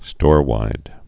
(stôrwīd)